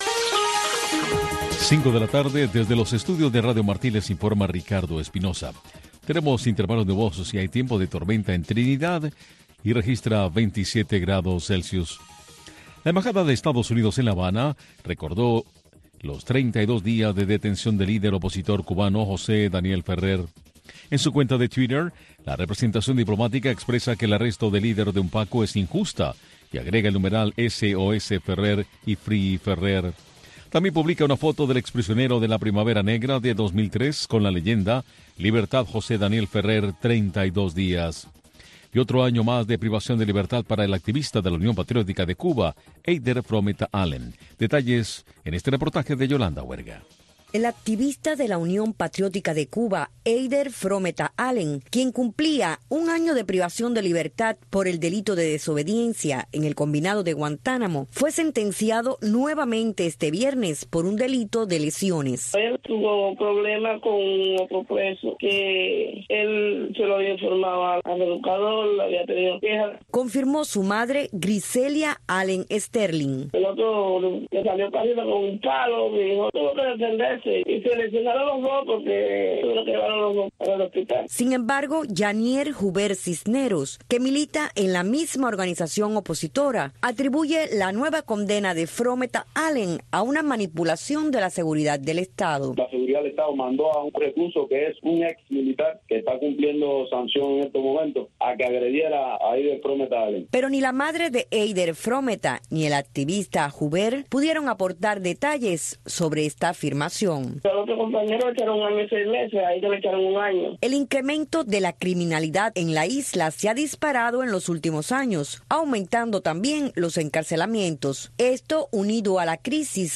Entrevistas e informaciones con las voces de los protagonistas desde Cuba. Servirá de enlace para el cubano conozca lo que sucede en el país sin censura.